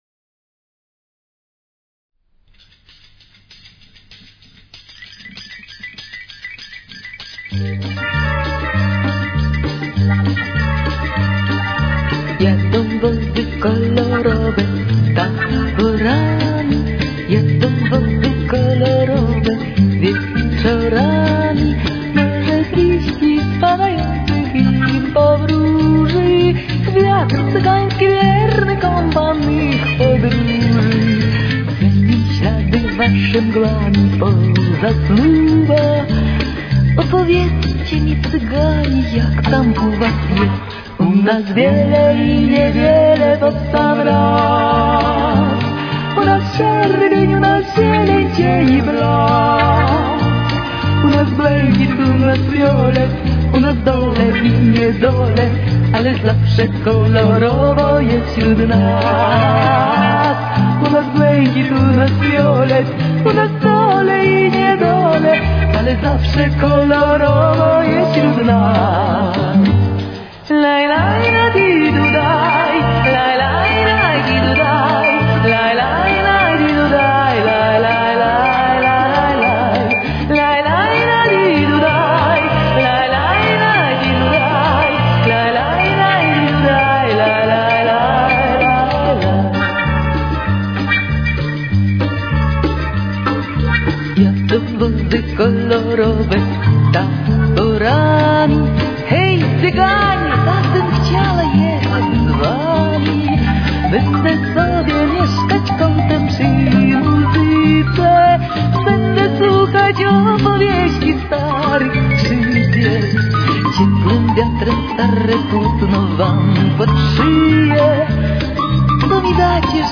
с очень низким качеством (16 – 32 кБит/с)
Темп: 100.